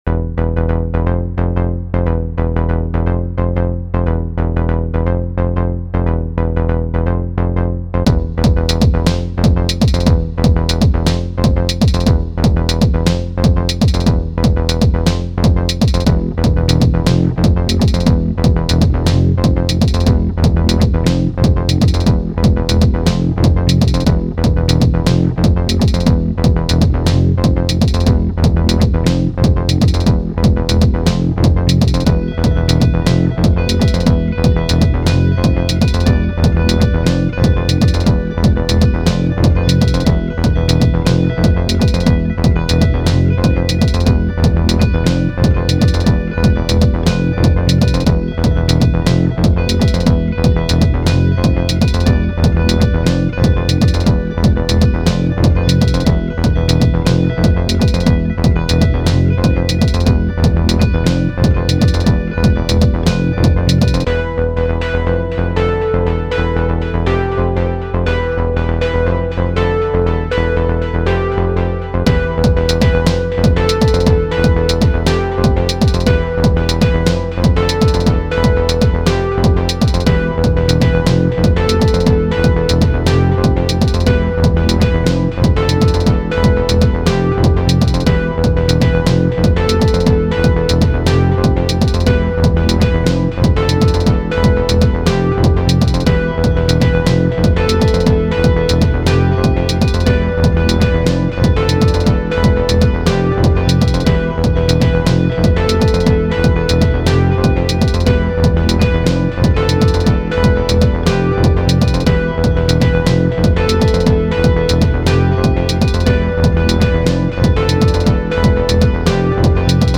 Electric Guitar in Electronic Music – Who’s Doing It Well?